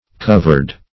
covered - definition of covered - synonyms, pronunciation, spelling from Free Dictionary
Covered \Cov"ered\ (k?v"?rd), a.